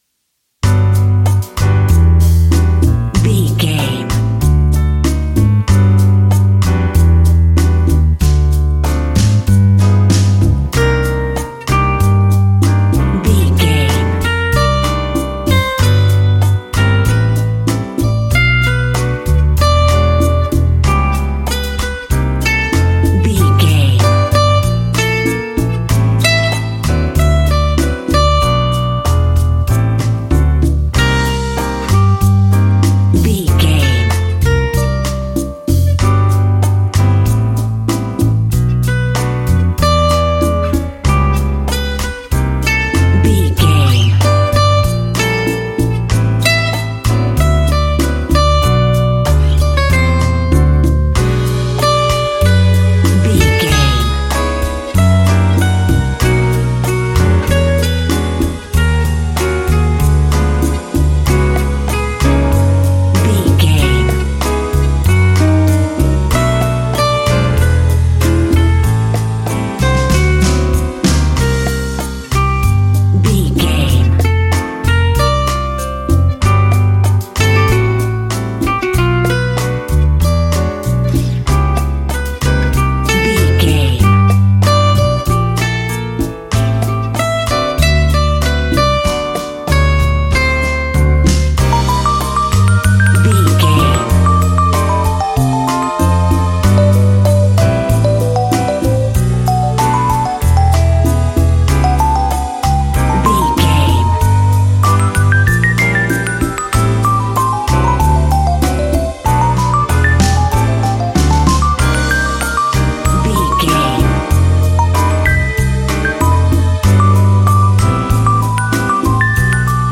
An exotic and colorful piece of Espanic and Latin music.
Ionian/Major
groove
romantic
maracas
percussion spanish guitar
latin guitar